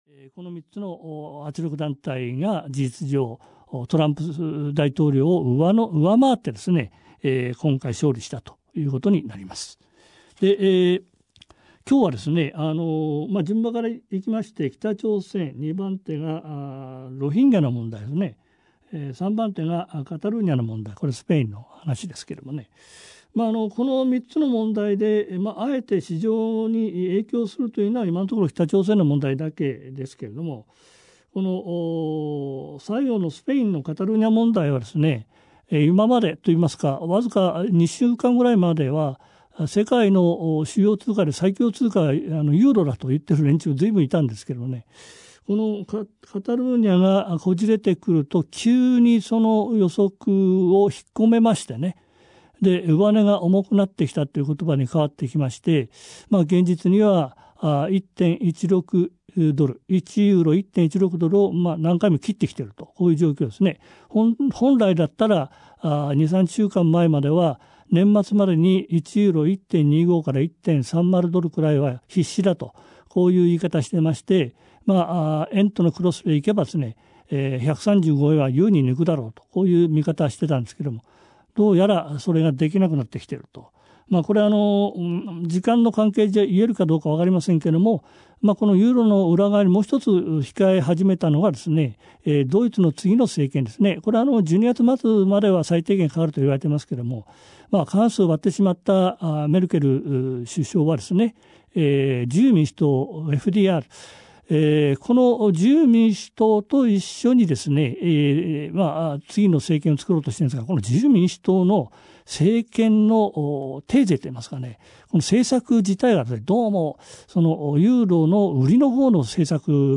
[オーディオブックCD]